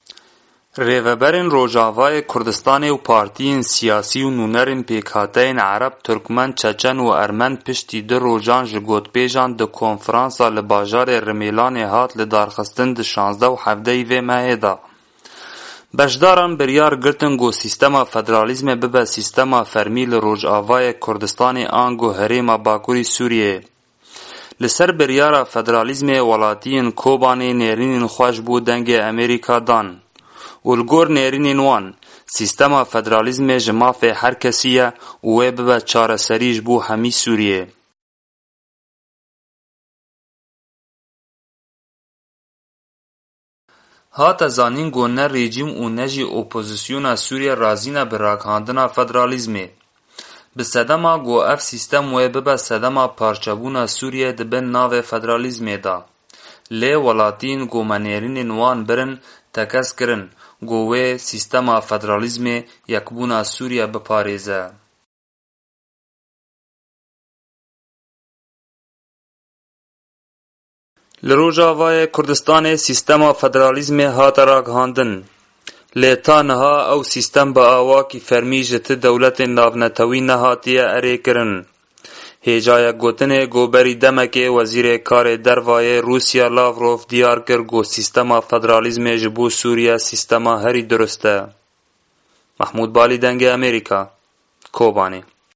Me jî çend welatîyên pirsî ku ew li ser ragihandina federalîzmê çi difikrin.